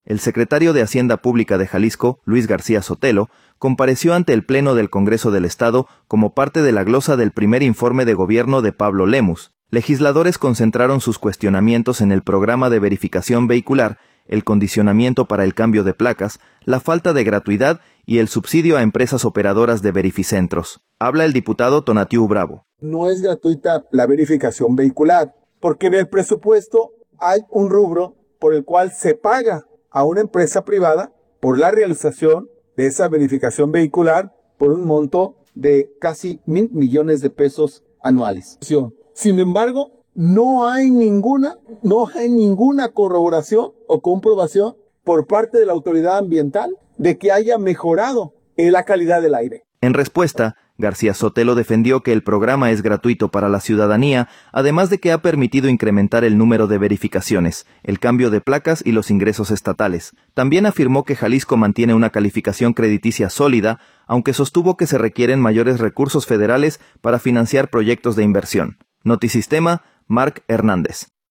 El secretario de Hacienda Pública de Jalisco, Luis García Sotelo, compareció ante el pleno del Congreso del Estado como parte de la glosa del primer informe de Gobierno de Pablo Lemus. Legisladores concentraron sus cuestionamientos en el programa de verificación vehicular, el condicionamiento para el cambio de placas, la falta de gratuidad y el subsidio a empresas operadoras de verificentros. Habla el diputado Tonatiuh Bravo.